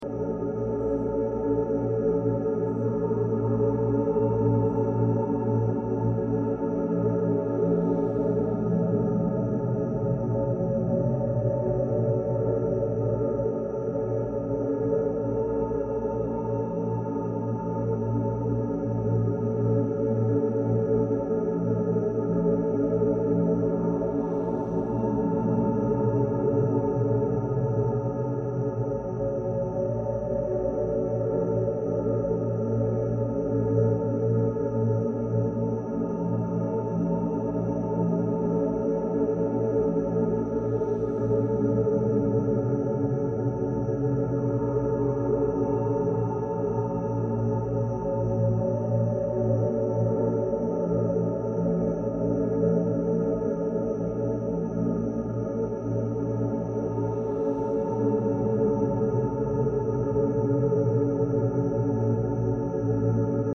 888Hz + 528Hz Healing Frequency sound effects free download
Experience the powerful blend of 888Hz and 528Hz healing frequencies — designed to attract miracles, abundance, and deep emotional healing while you sleep. 🌟 888Hz is the vibration of infinite abundance and financial flow, while 528Hz is known as the "Miracle Tone", resonating with love, DNA repair, and transformation.